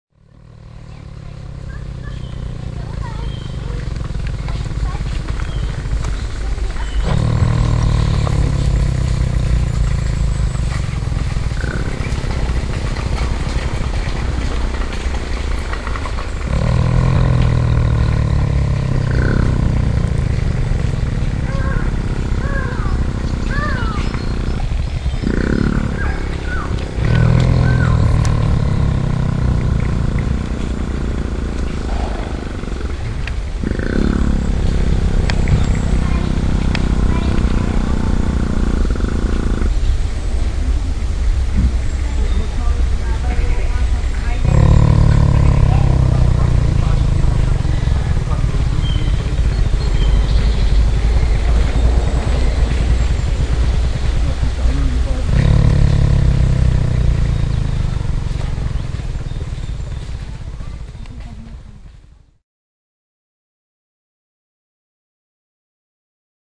41 field recordings 1999-2003
Among the field recordings one can find soundscape-compositions, noise and sounds of the environment compositionally and technically arranged.